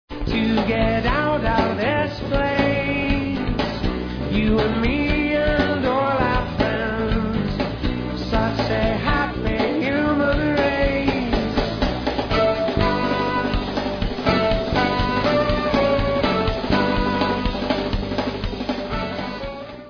Jamming poprock world beat flavour